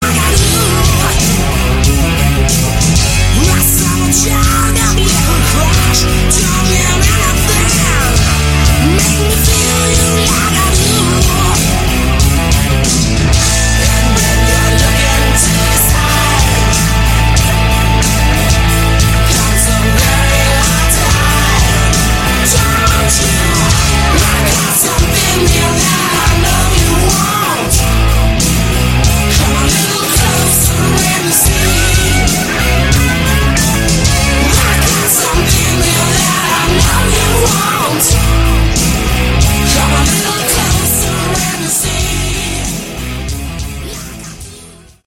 Category: Heavy Metal/Glam